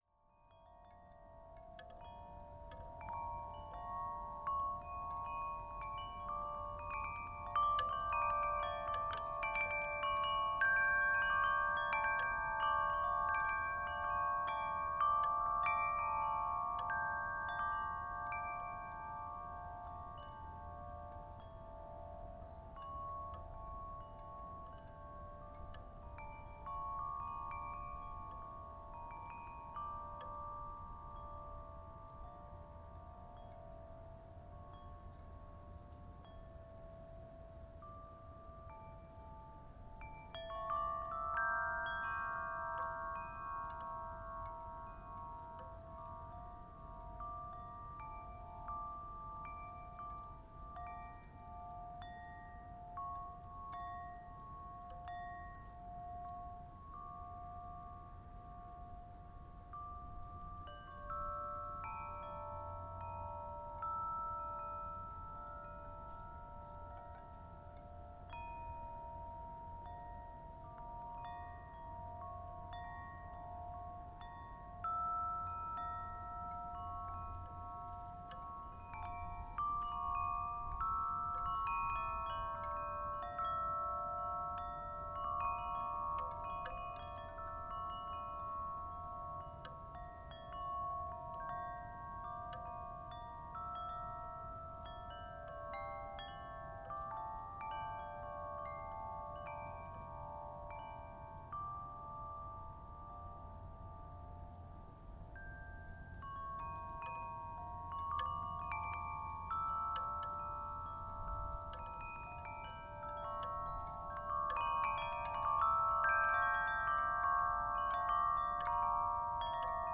Wind Chimes, Teign Gorge near Castle Drogo - Gypsy Soprano + Mezzo - Bamboo (1) - excerpt
Category 🌿 Nature
bamboo Castle-Drogo chimes Devon England field-recording Gypsy mezzo sound effect free sound royalty free Nature